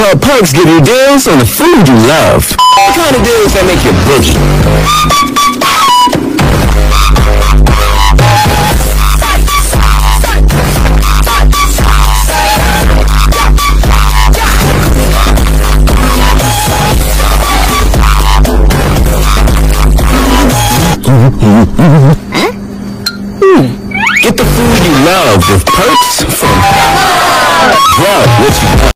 Car be like: sound effects free download
You Just Search Sound Effects And Download. tiktok sound effects funny Download Sound Effect Home